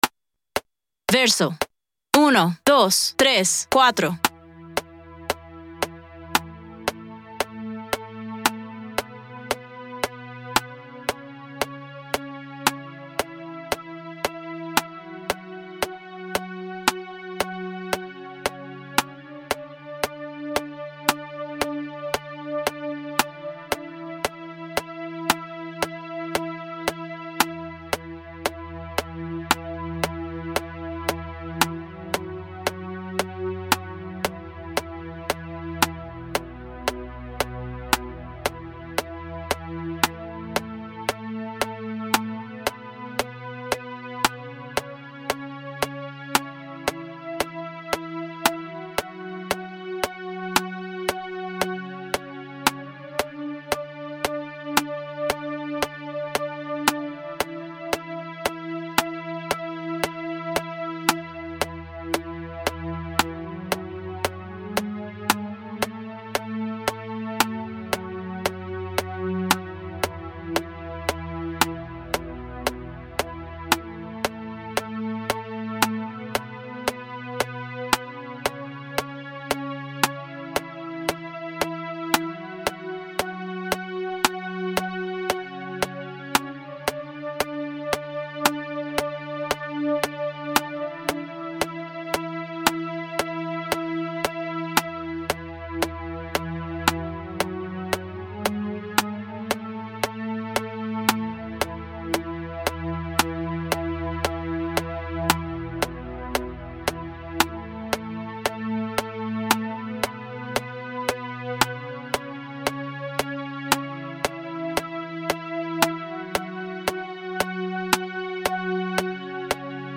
Nota: D
BPM: 114
Measure: 4/4
Click y Guide
Guitarra Acústica, Percusión y Pads